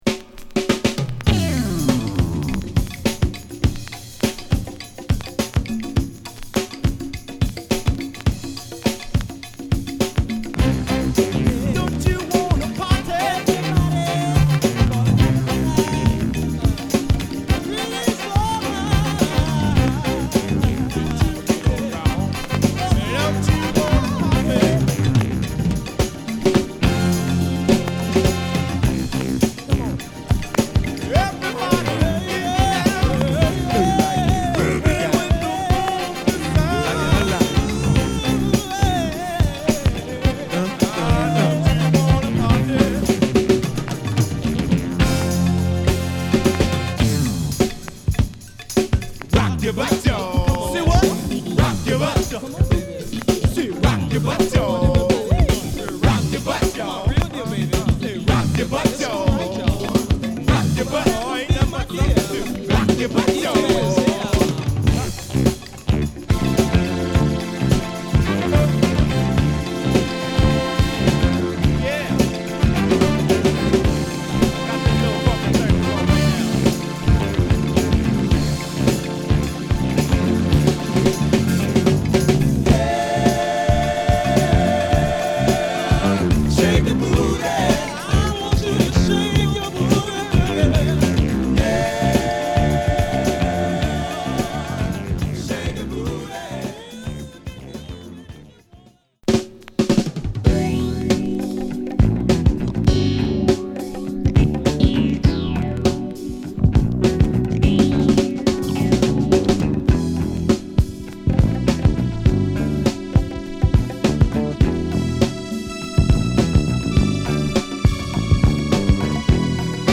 ＊SideA頭に傷。ノイズ出ます。